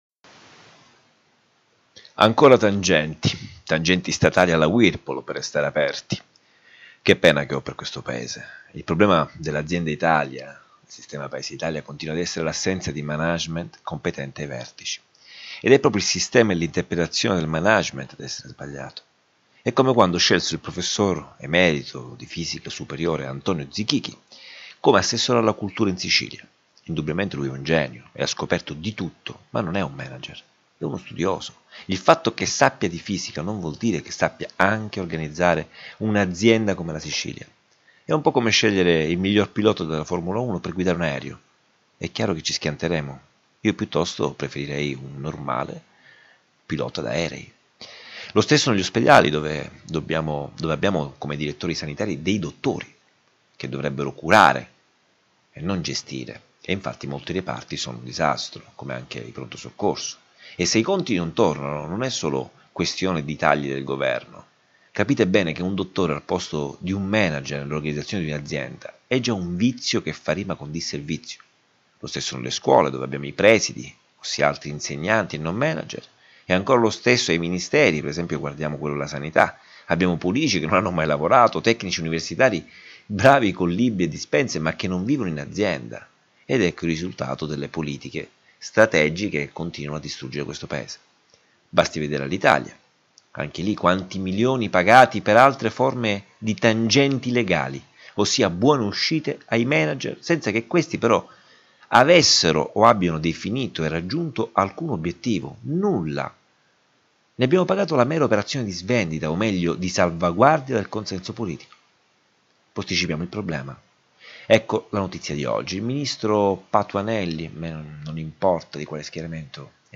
I Minuti d’audio Riproducono la riflessione dell’articolo a voce alta, perché abbiano accesso all’ascolto i ciechi, a chi lavorando non ha tempo o chi preferisce ascoltare, e quelli tra noi che pur avendo la vista sono diventati i veri Non Vedenti.